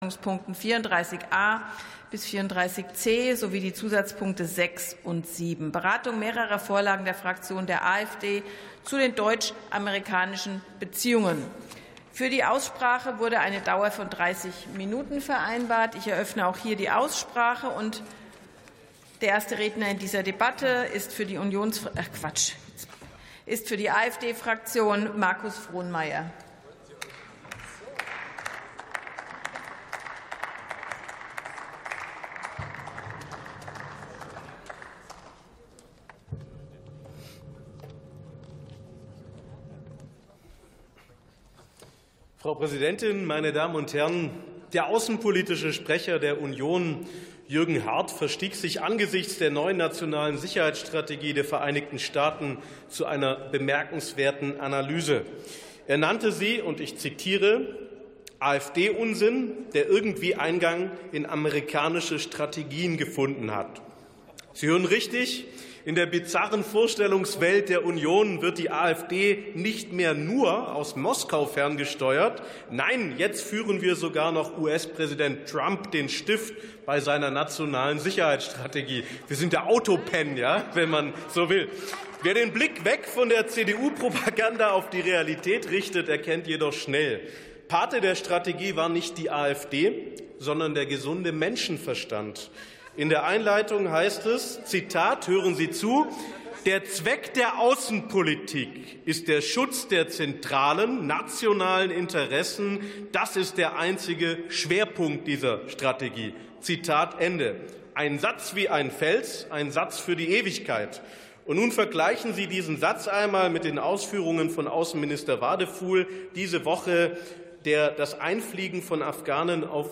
51. Sitzung vom 19.12.2025. TOP 34, ZP 6, 7: Deutsch-amerikanische Beziehungen ~ Plenarsitzungen - Audio Podcasts Podcast